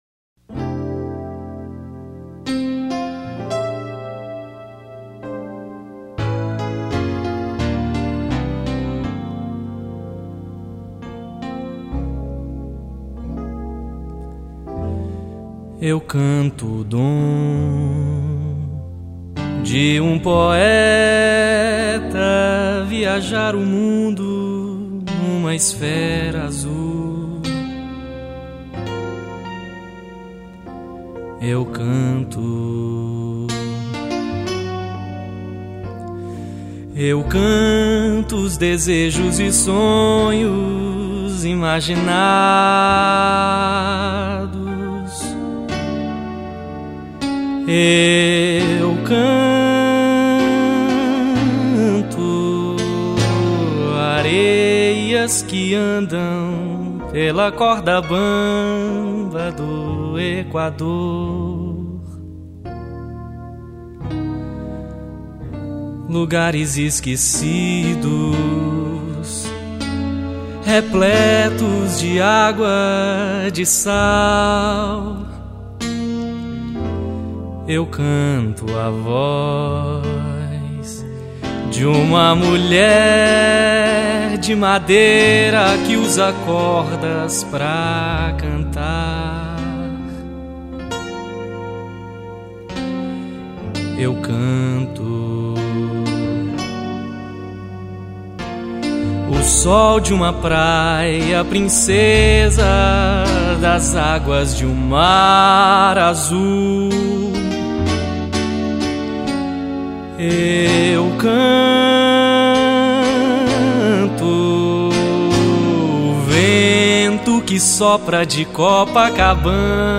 Piano Acústico
Bateria
Baixo Elétrico 6